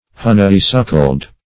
Honeysuckled \Hon"ey*suc`kled\